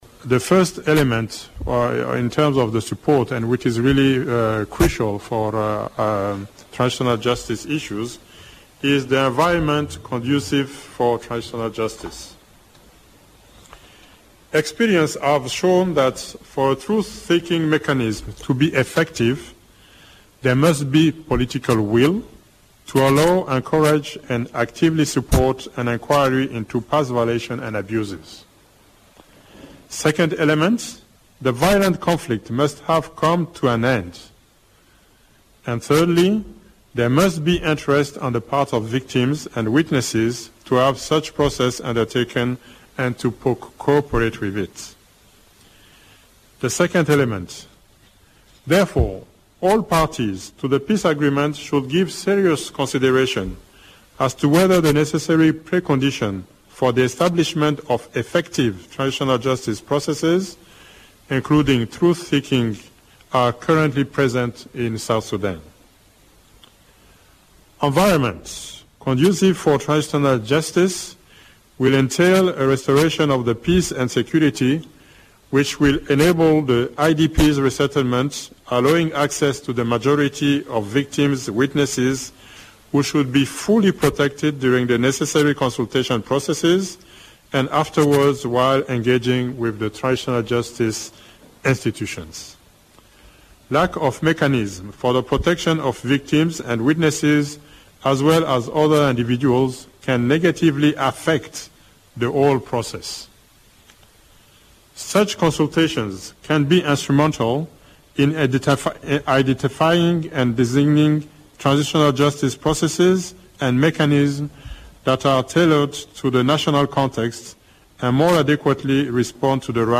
Addressing a weekly press briefing in Juba, Eugene, said a conducive environment is one that would enhance the capacities of critical institutional and civil society actors, to engage in and steer an inclusive, participatory, victim-centered and gender-sensitive transitional justice process in South Sudan.